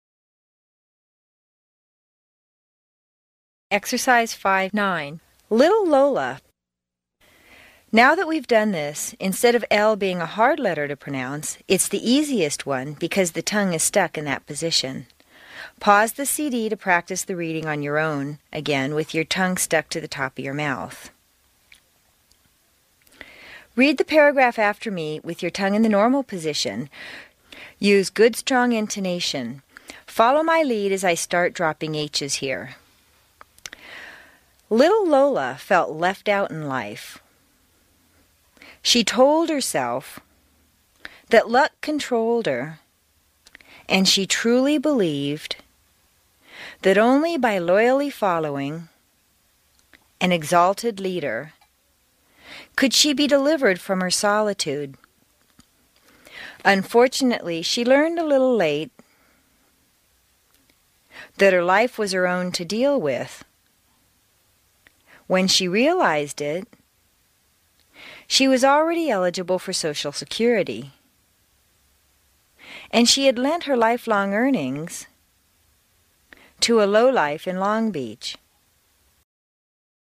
在线英语听力室美式英语正音训练第84期:练习9的听力文件下载,详细解析美式语音语调，讲解美式发音的阶梯性语调训练方法，全方位了解美式发音的技巧与方法，练就一口纯正的美式发音！